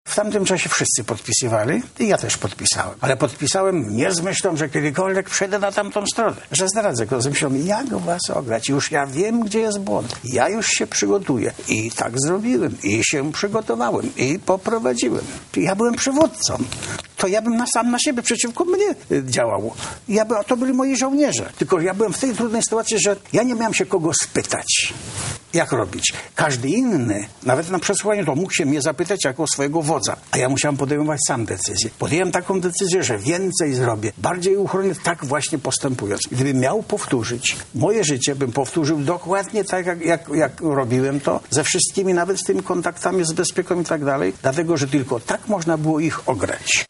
– mówił w Kropce nad i Lech Wałęsa